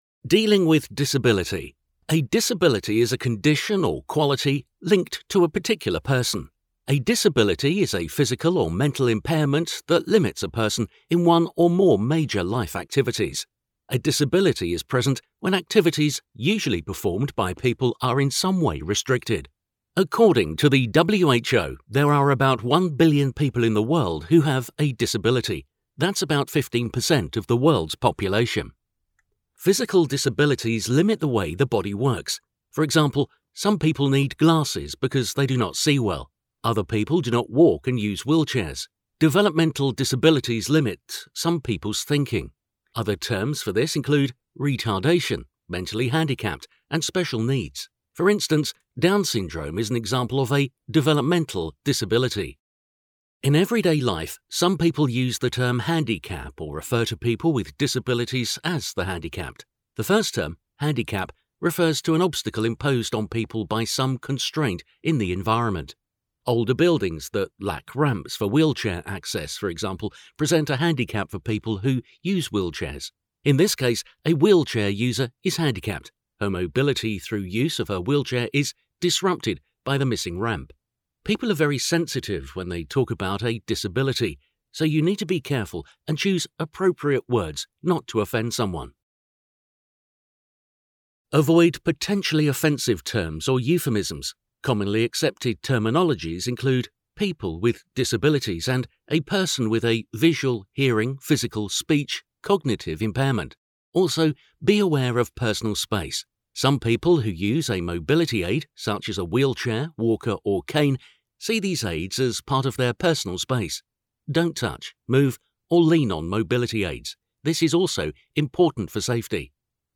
5-Lesson-Dealing-With-Disability.mp3